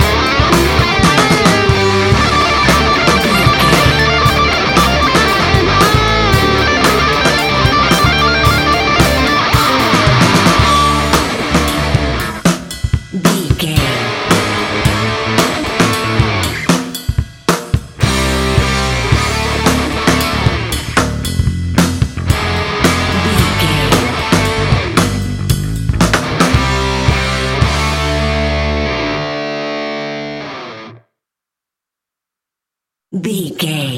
Ionian/Major
hard rock
heavy rock
distortion
instrumentals